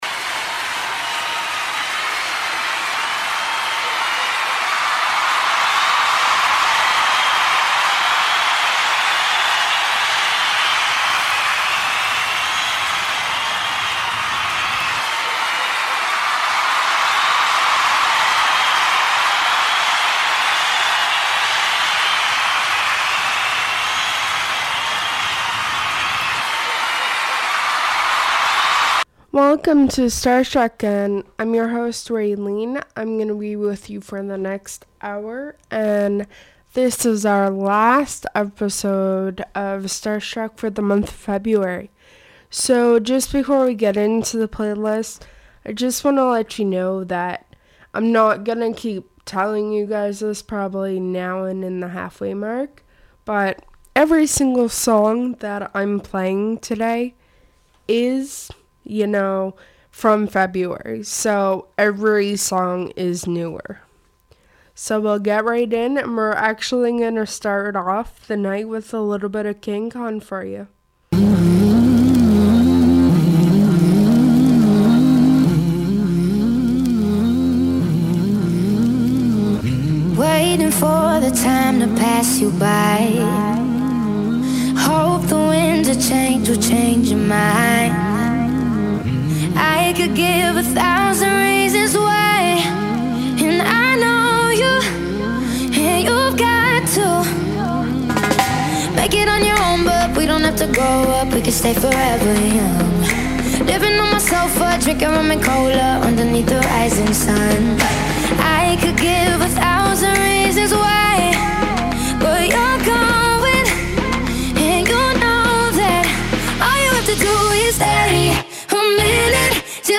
An Open Format Music Show - Pop, Acoustic, Alternative Rock,as well as Local/Canadian artists